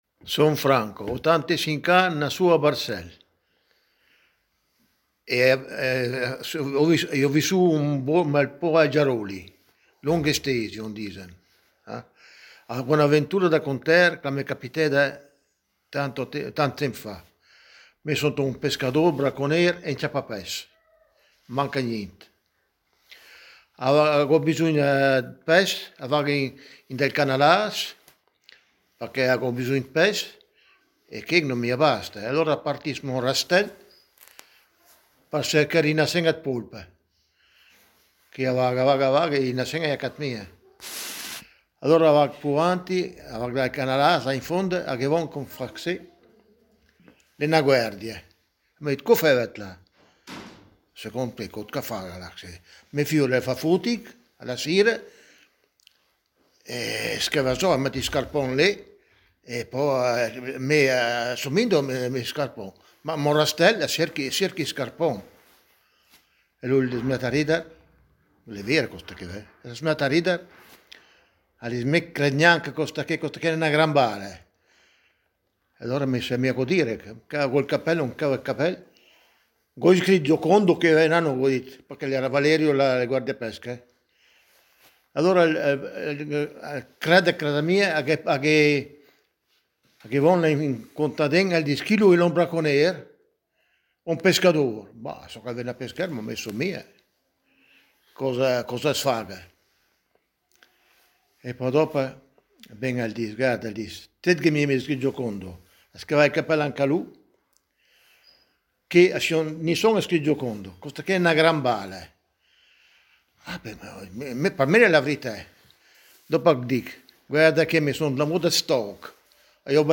Tuttavia, in seconda istanza, abbiamo anche sfruttato le molte conoscenze dirette che abbiamo, come gruppo di Léngua Mêdra, con persone parlanti dialetti ben caratterizzati.